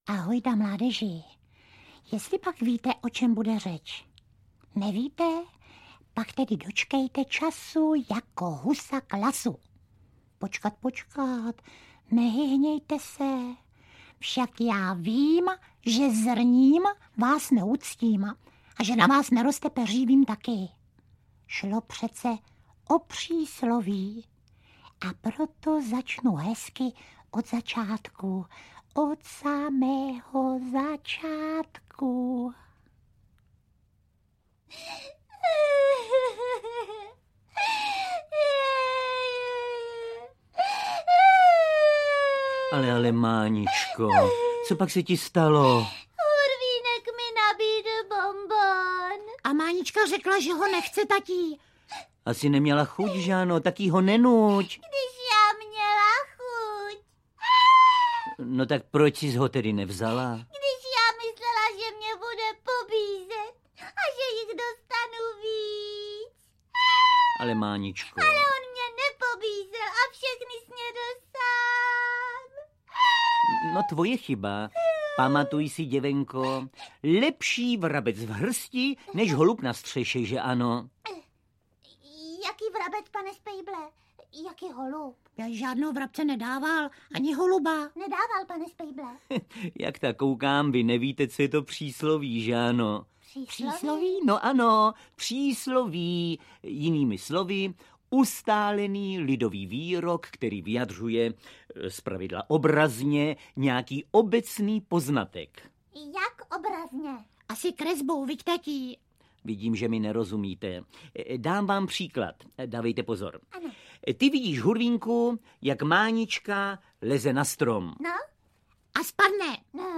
Jak Hurvínek do lesa volá, tak se Spejbl ozývá - Vladimír Straka, Miloš Kirschner - Audiokniha